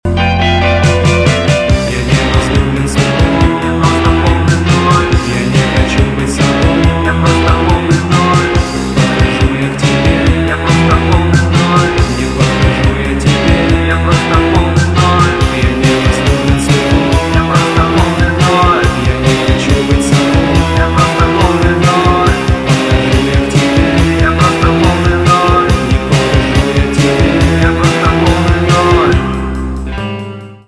инди
грустные , печальные , барабаны , гитара , депрессивные